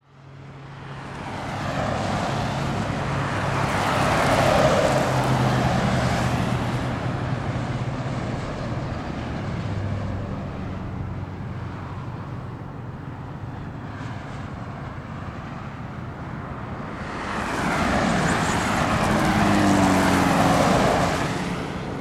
На этой странице собраны звуки автомобильной пробки — гудки машин, шум двигателей и общая атмосфера затора.
Звуки проезжей части, машины движутся потоком в 3D